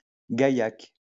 Gaillac (French pronunciation: [ɡajak]